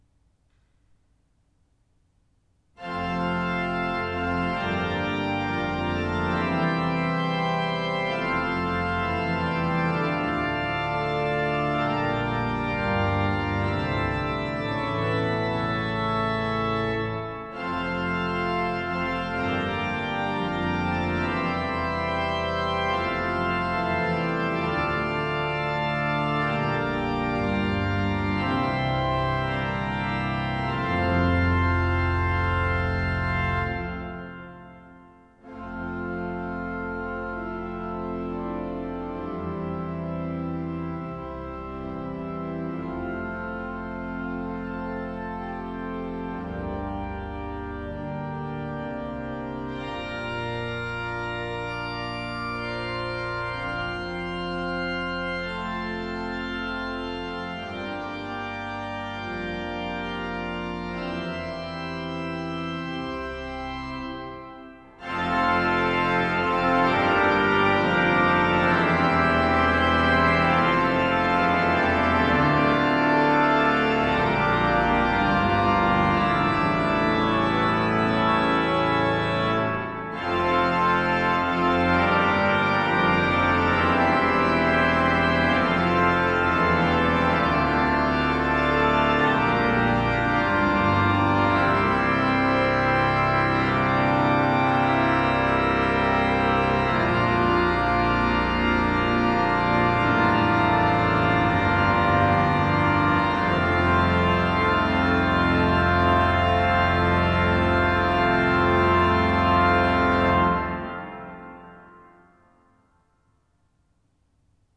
Bodø domkirke
Fanfare Min egen fanfare.
Bodø Domkirke   ZOOM H4n 14.06.2023